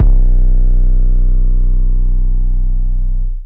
[808] carousel.wav